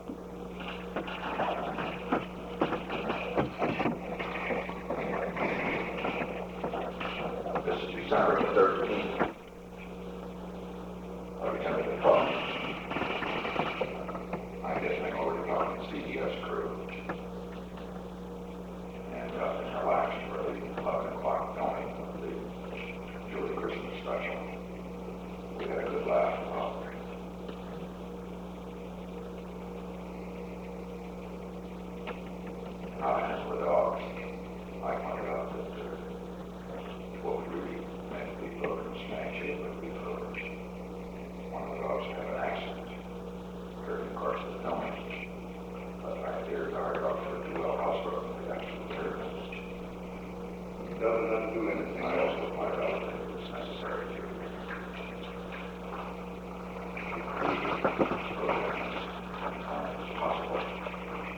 Secret White House Tapes
Location: Oval Office
The President dictated a memorandum for the file.